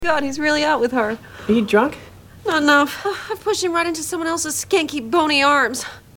Category: Television   Right: Personal
Tags: Lindsay Bluth Funke Lindsay from Arrested Development Lindsay clips Arrested Development quote Portia de Rossi